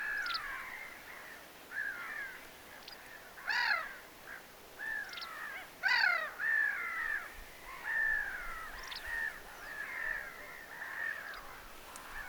västäräkin mielestäni aika hienoja ääniä
vastarakin_hienoja_aania.mp3